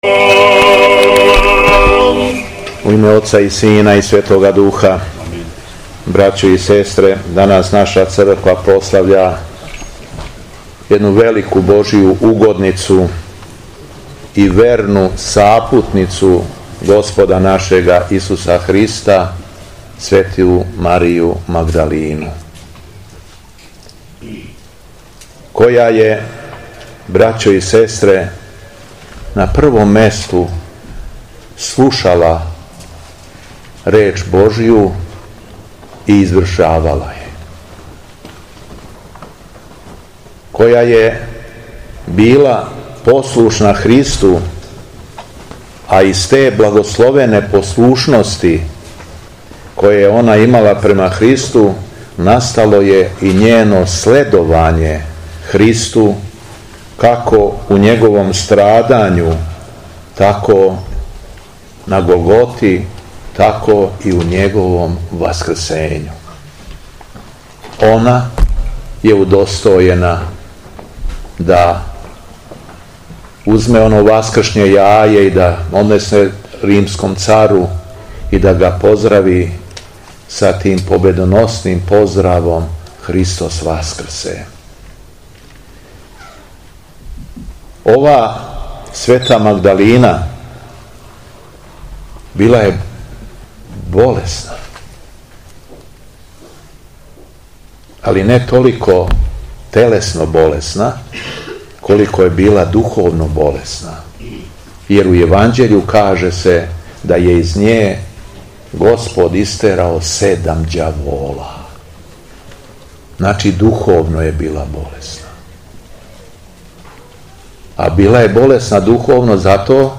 Беседа Његовог Високопреосвештенства Митрополита шумадијског г. Јована
Надахнутом беседом Митрополит Јован обратио се верном народом: